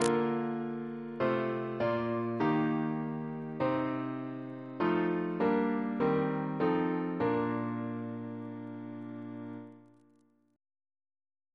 Single chant in B♭ Composer: Henry Hiles (1826-1904) Reference psalters: OCB: 126